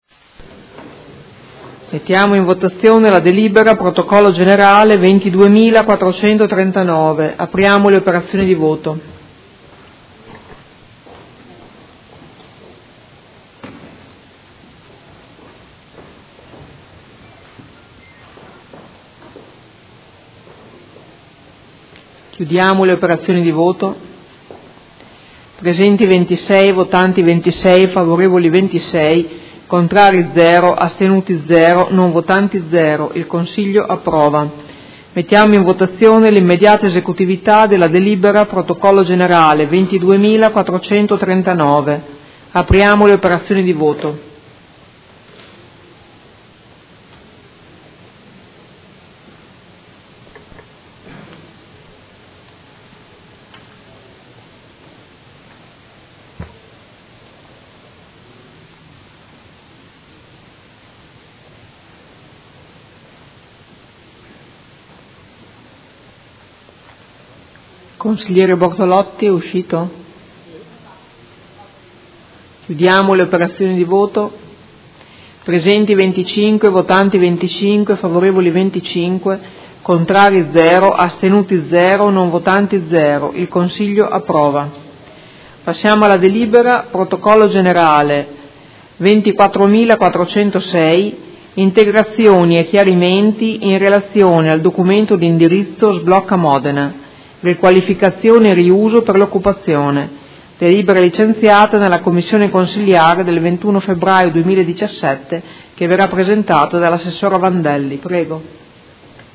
Presidentessa — Sito Audio Consiglio Comunale